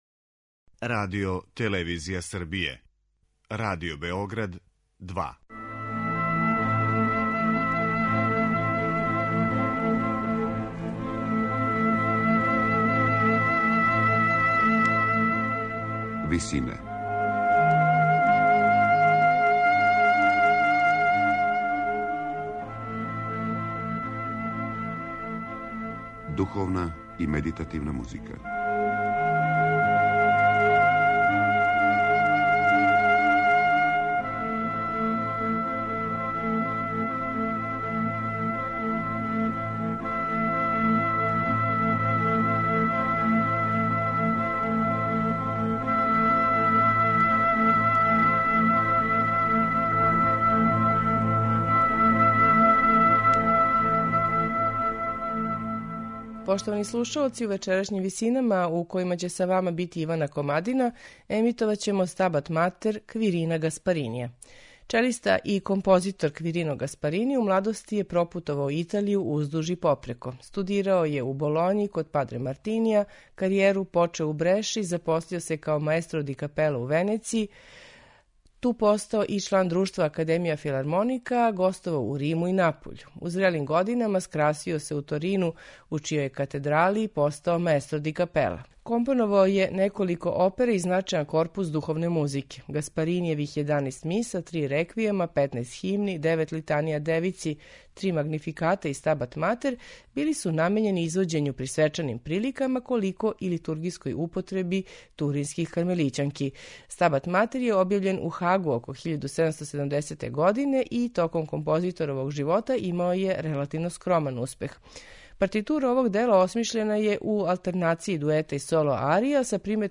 сопрани
алт и ансамбл Страдивариа